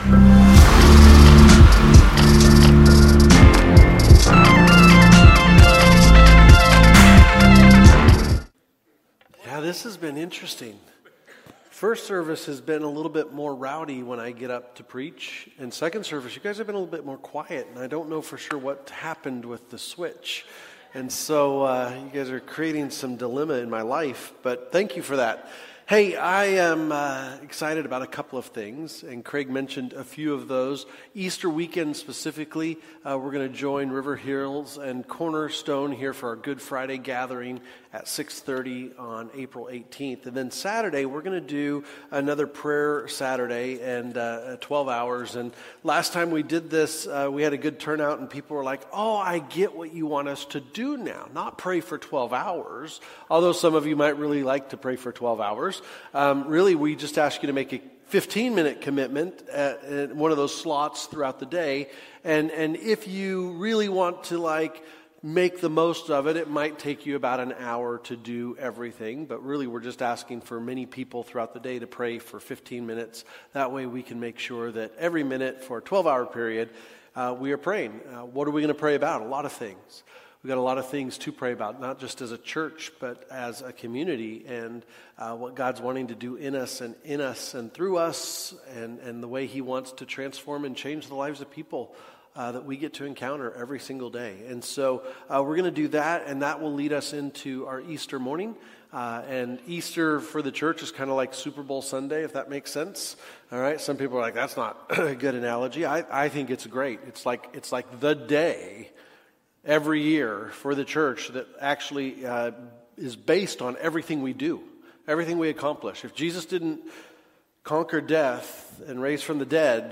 Sermons | Mountainview Christian Church